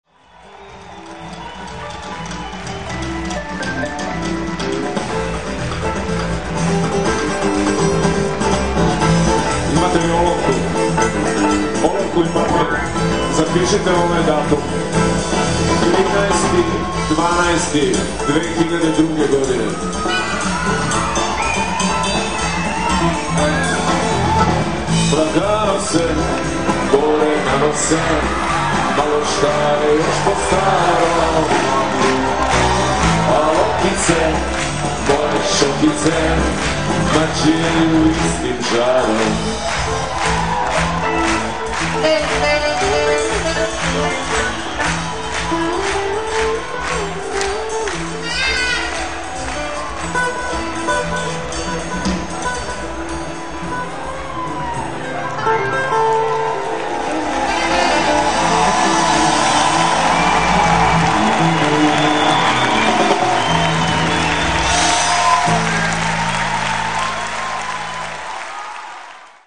Uživo
2002 Ljubljana